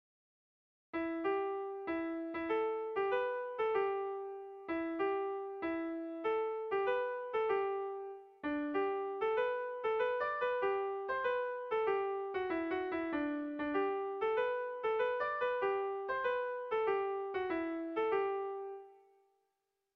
AABD1BD2